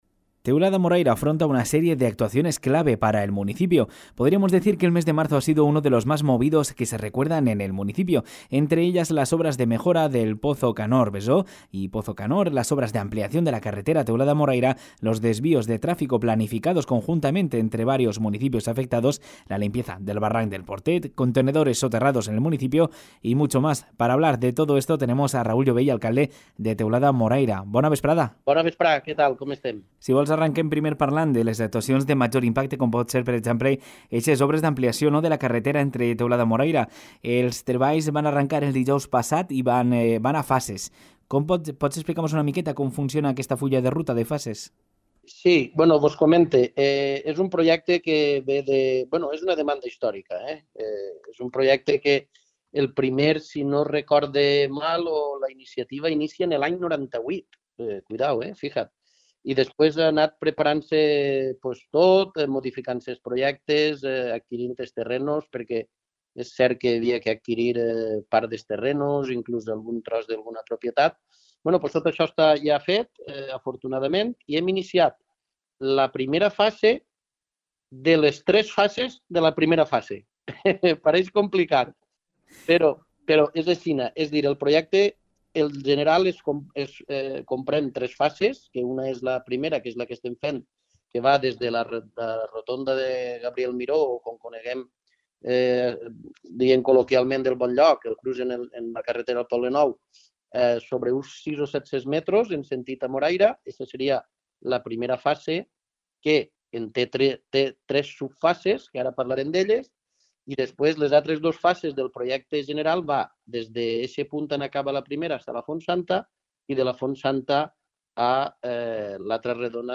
Hablamos con el alcalde sobre mejoras en la carretera, la limpieza de barrancos, los pozos y los contenedores
Con estas cartas sobre la mesa, en el Informativo de Ràdio Litoral hemos podido conversar con el alcalde del municipio, Raúl Llobell.
Entrevista-Raul-Llobell-Abril-Actuaciones.mp3